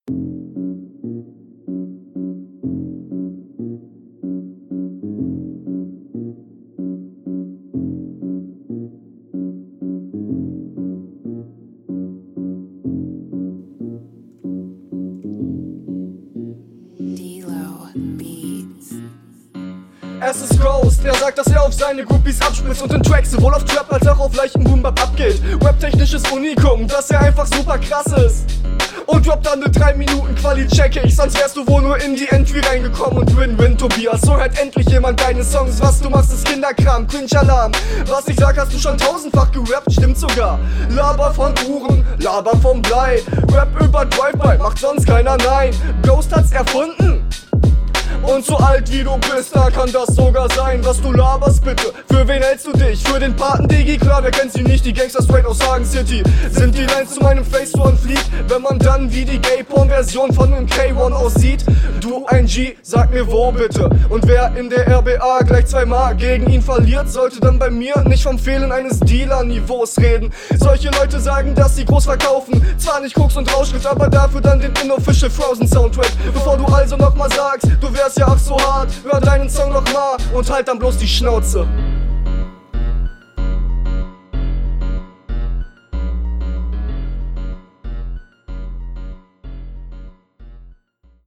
Beat ist nice! Stimme kommt nicht so geil.
Klingt nicht so sauber wie deine erste runde.